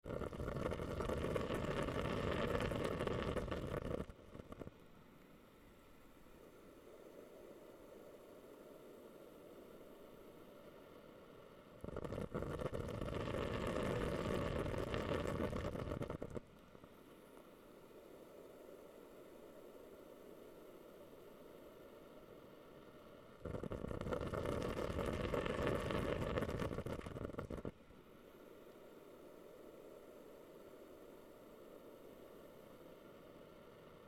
Бульканье пара в увлажнителе воздуха